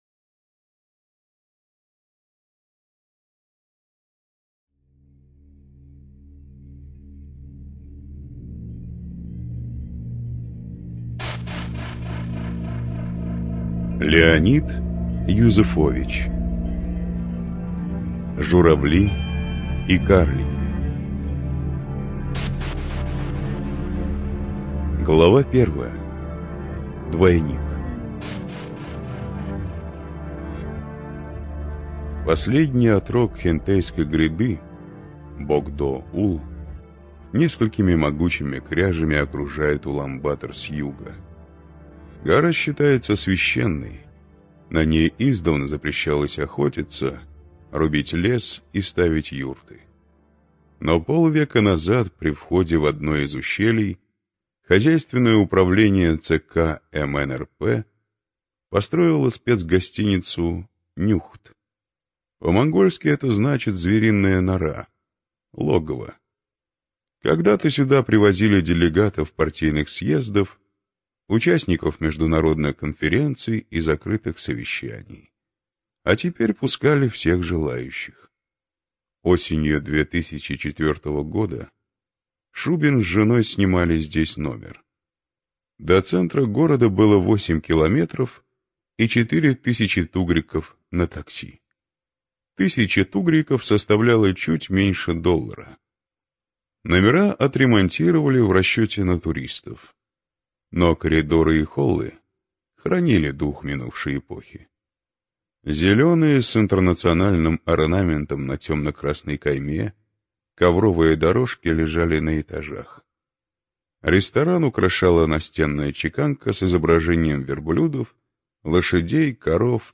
ЖанрПриключения